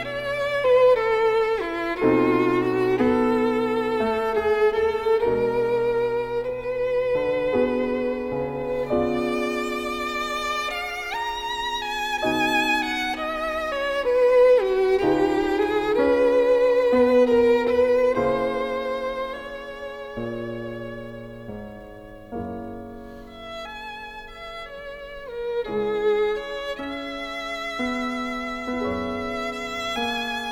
Sonate pour violon et piano, la majeur